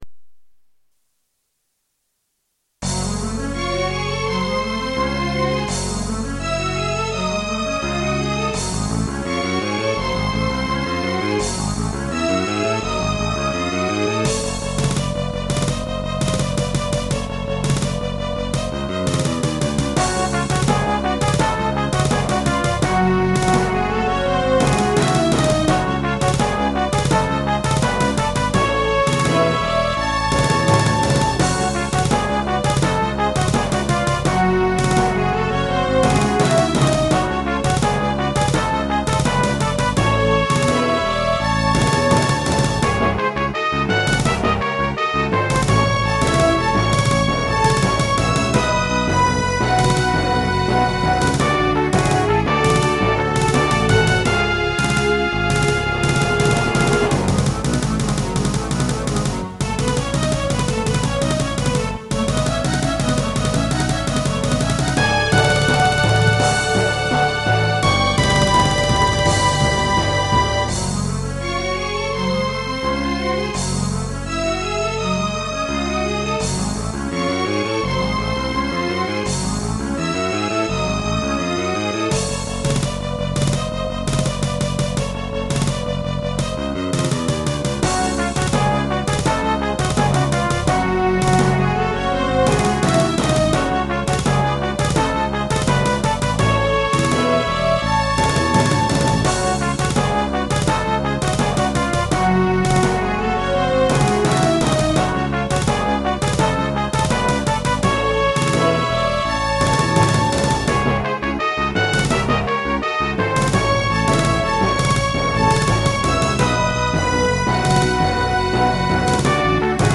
管理人が作ったMIDI集です
怪しいメロディーラインが特徴の曲です。